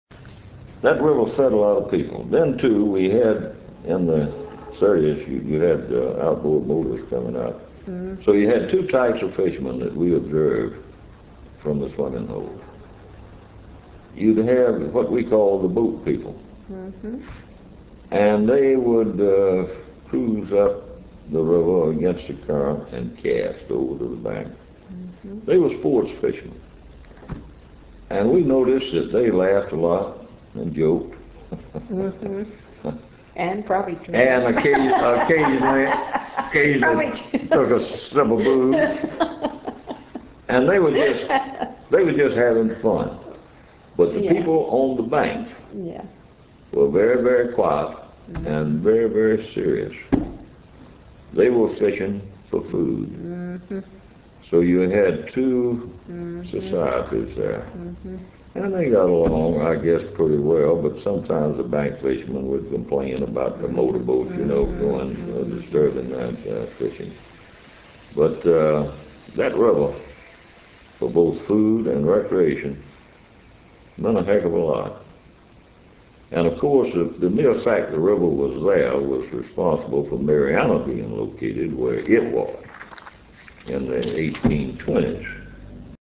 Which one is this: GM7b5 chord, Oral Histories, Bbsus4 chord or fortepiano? Oral Histories